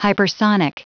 Prononciation du mot hypersonic en anglais (fichier audio)
Prononciation du mot : hypersonic